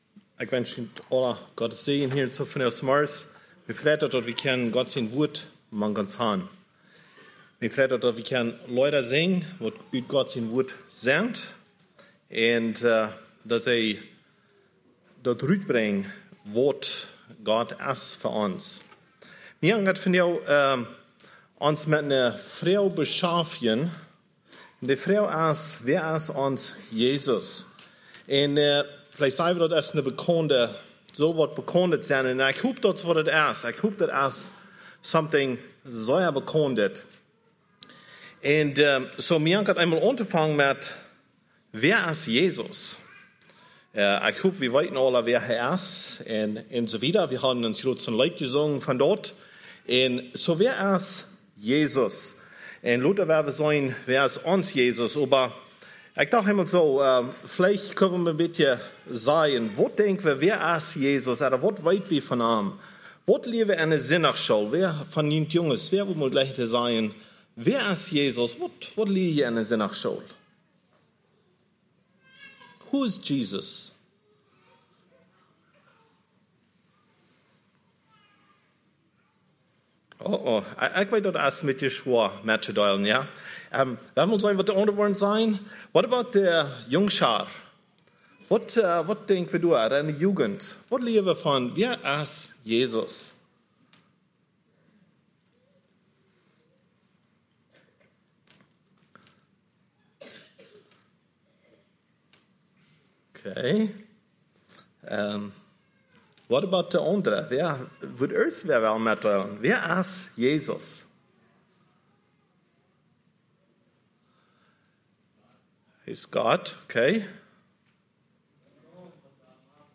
message brought on Oct. 29, 2023